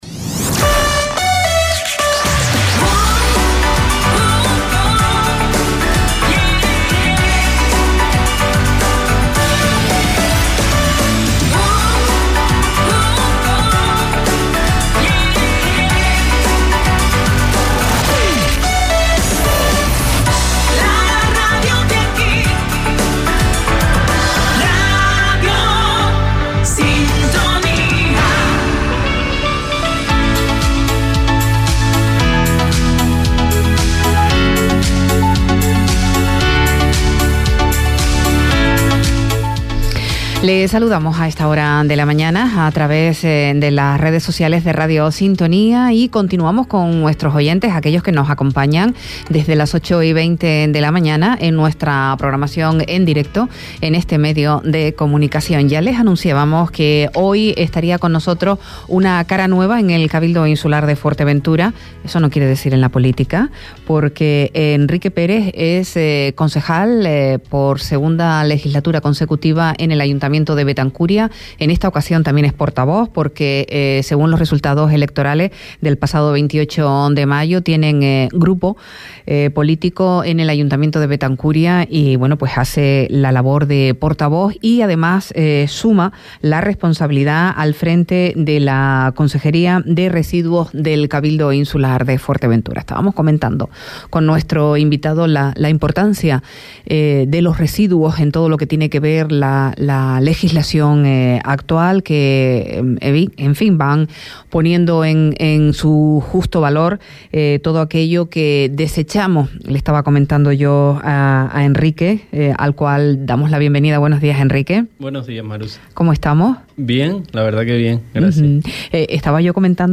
Entrevista a Enrique Pérez, consejero de Residuos -11.07.23 Deja un comentario
Entrevistas